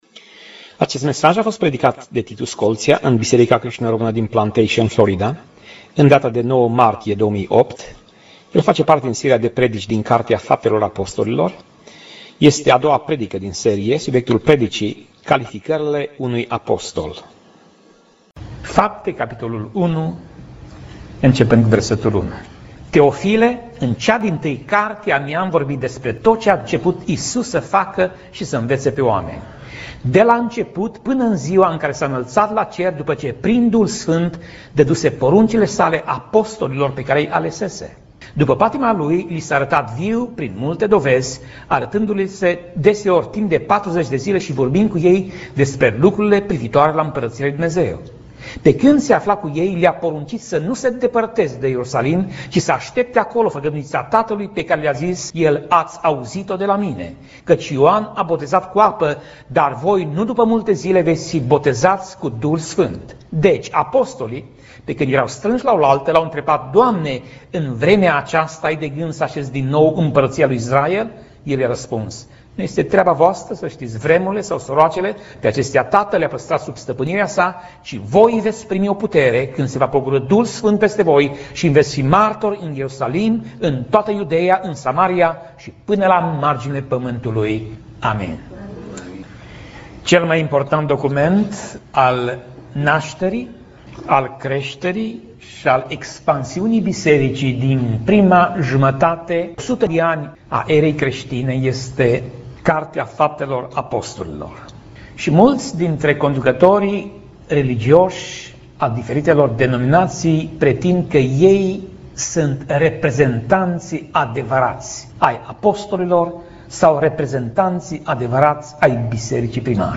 Tip Mesaj: Predica